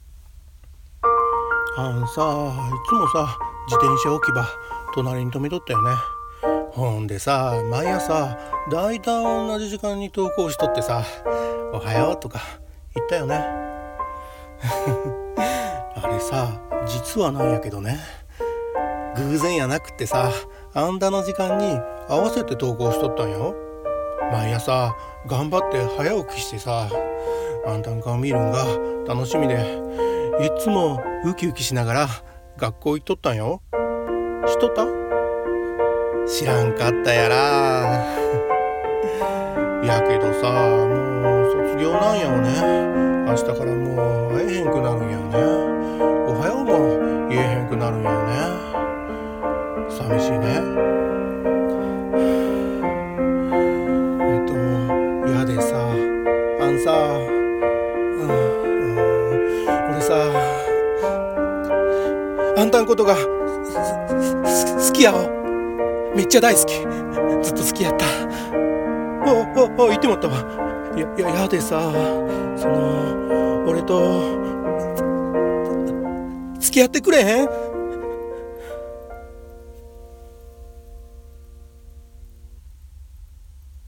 岐阜弁で告白